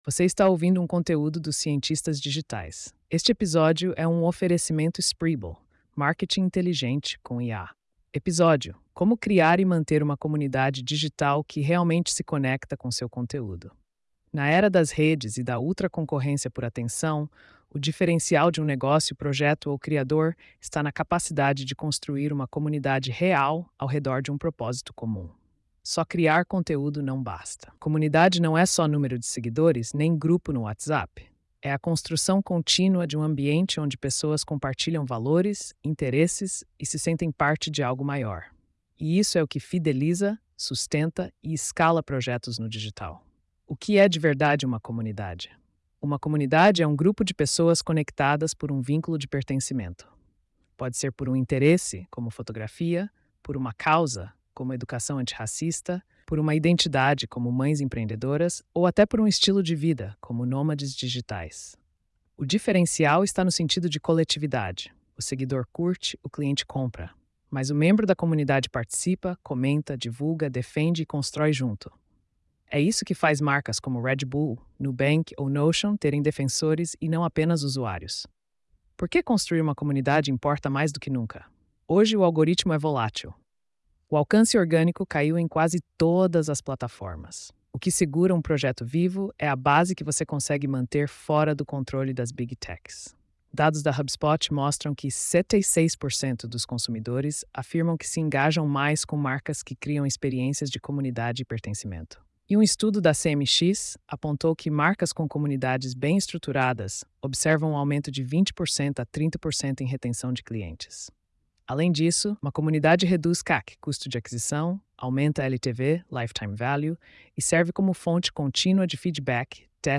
post-3426-tts.mp3